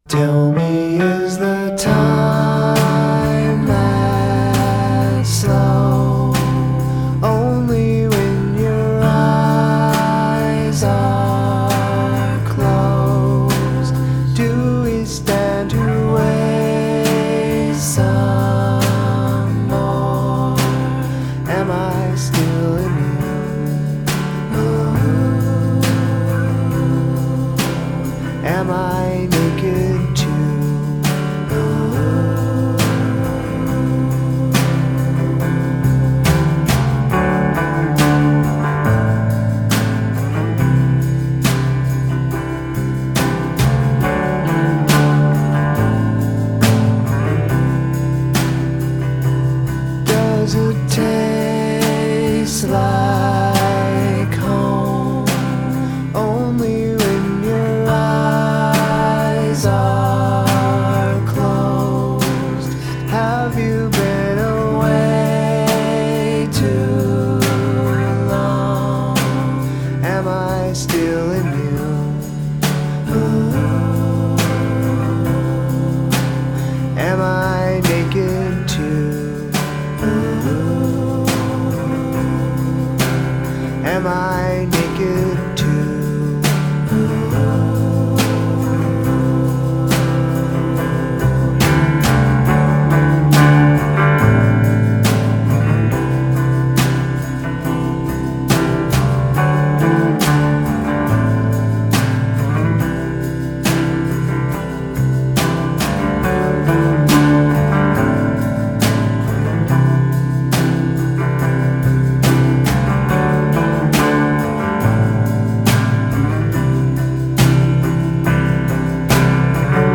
the perfect music for a damp gray day.